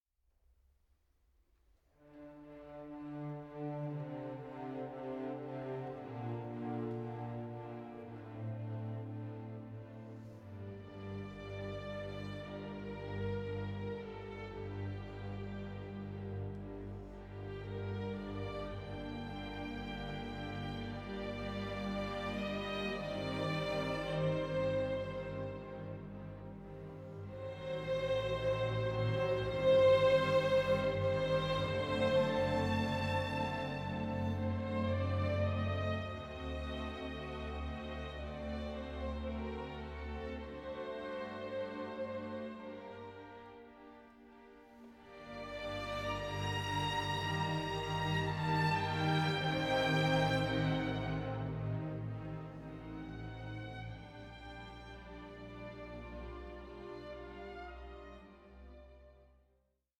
(48/24) Stereo  18,99 Select
multi-channel surround sound